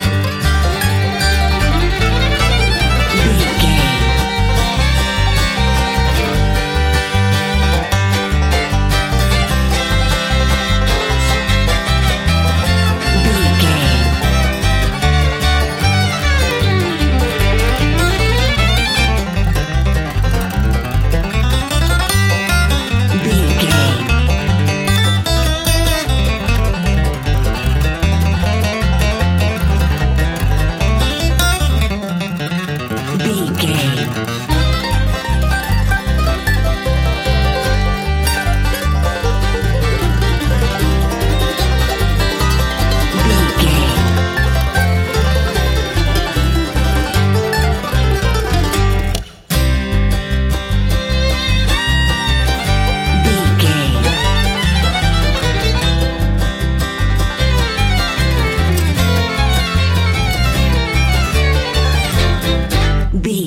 Ionian/Major
banjo
violin
double bass
acoustic guitar
Pop Country
country rock
bluegrass
happy
uplifting
driving
high energy